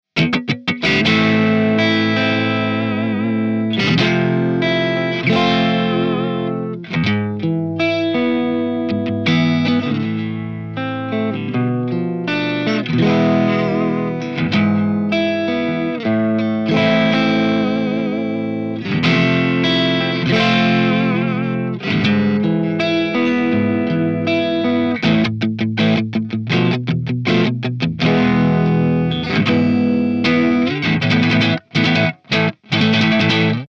Chords
RAW AUDIO CLIPS ONLY, NO POST-PROCESSING EFFECTS